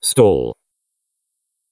stall_voice.wav